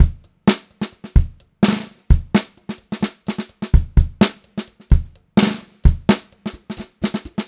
128GRBEAT1-R.wav